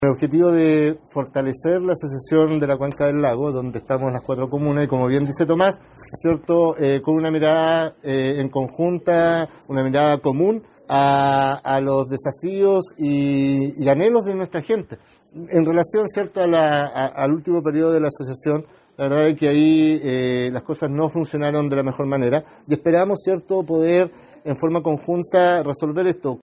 El reelecto edil de Llanquihue, Víctor Angulo, subrayó que el objetivo es fortalecer la Asociación de municipios de la cuenca del lago, ya que reconoció que en el último tiempo no funcionó de la mejor manera.